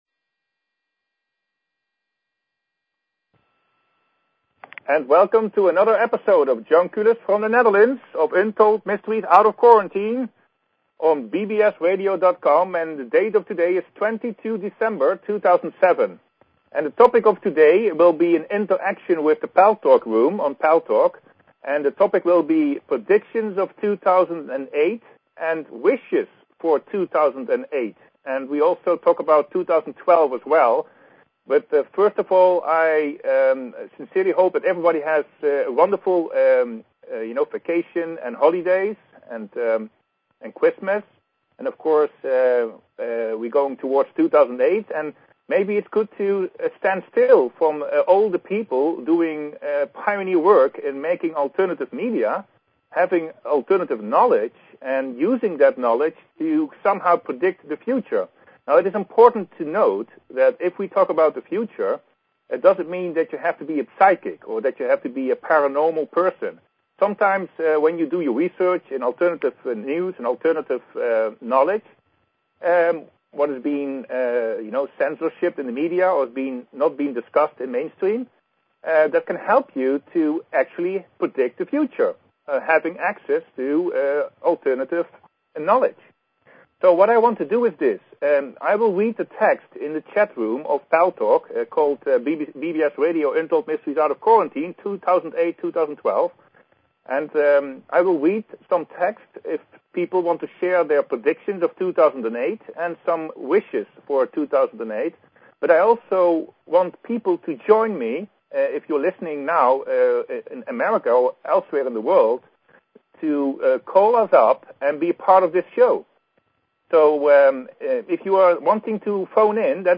Talk Show Episode, Audio Podcast, UntoldMysteries and Courtesy of BBS Radio on , show guests , about , categorized as